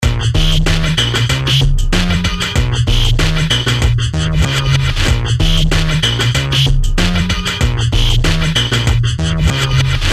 94bpm拖刀 " 94bpm拖刀3
描述：当hip_hop是真实和粗糙的时候。3创造的原因..........bass.......，并打出。
Tag: 贝斯 击败 臀部 跳跃 理性 粗糙 街道